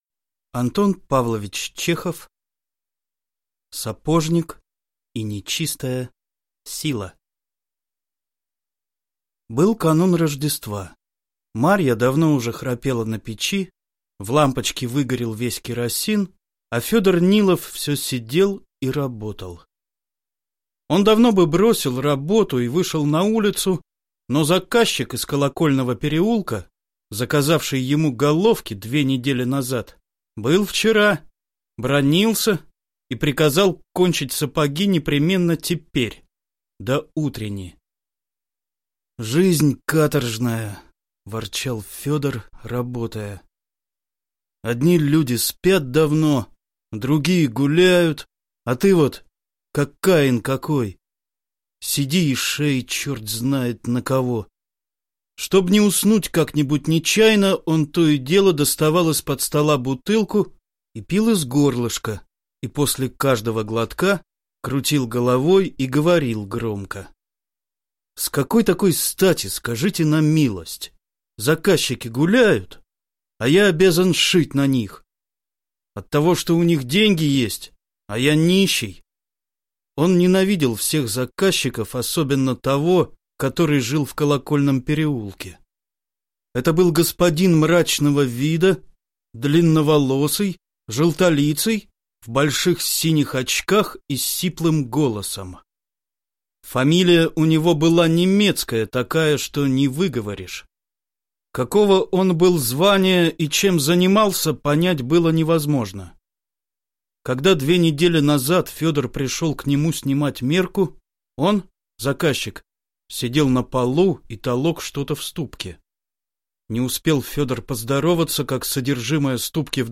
Аудиокнига Сапожник и нечистая сила | Библиотека аудиокниг